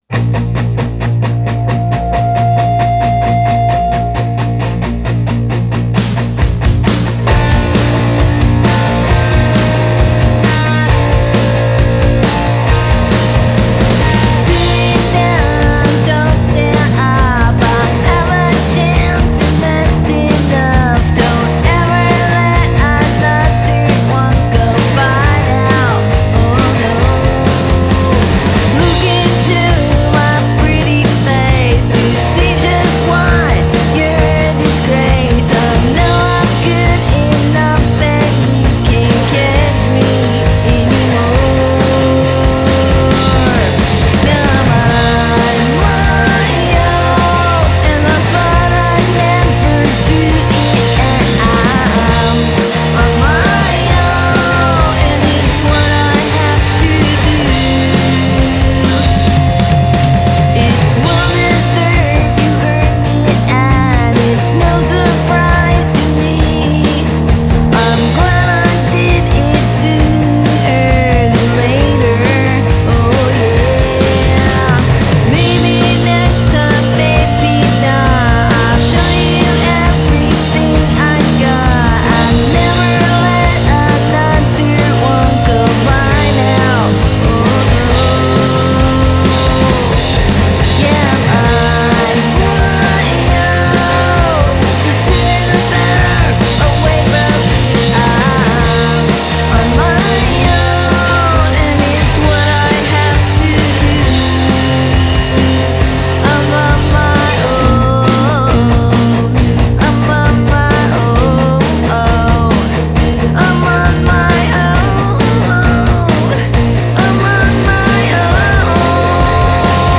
punk band